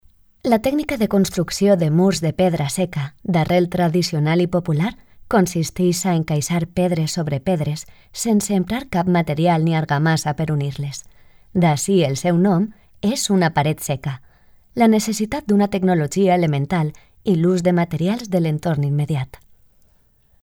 Valencian female voice talent locutor